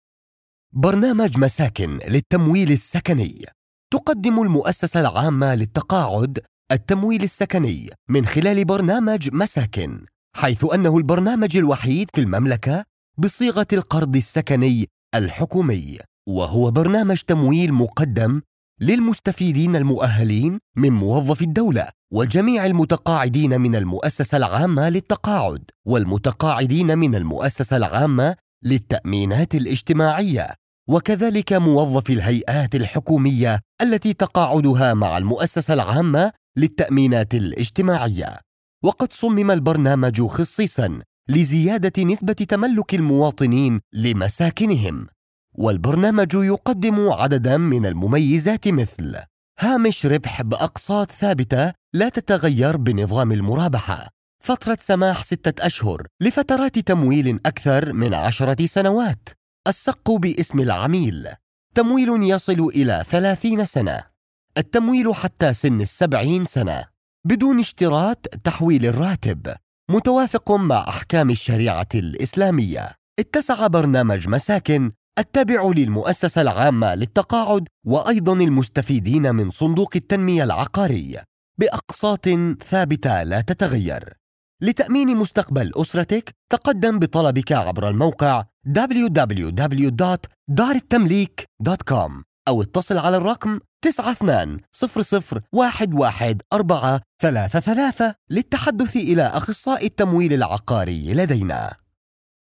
Male
Adult (30-50)
I can do Arabic Voice Over - Fus,ha Standard and , Gulf Fus,ha And Egyptian Fus,ha - Deep Voice
Corporate
Arabic Fusha Masakn
All our voice actors have professional broadcast quality recording studios.